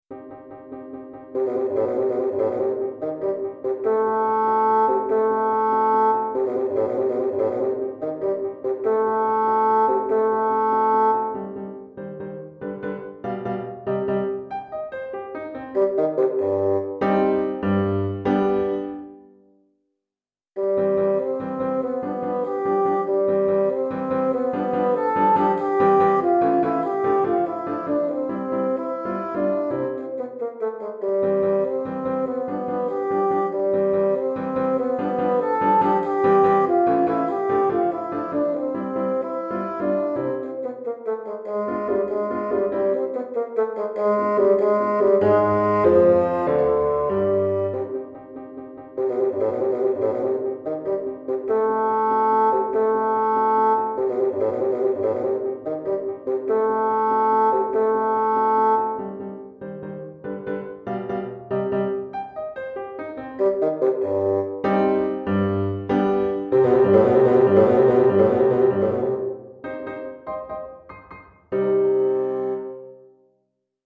Basson et Piano